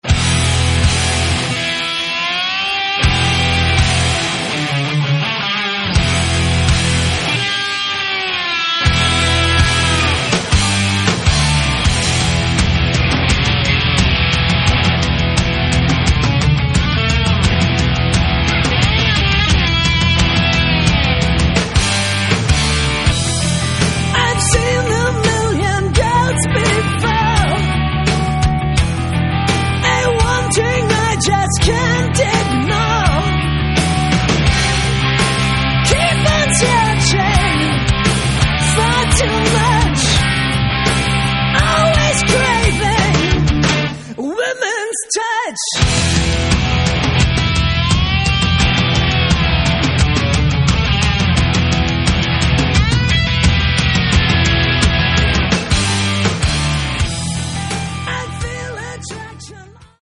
Рок
вокал, гитары
бас
ударные
клавишные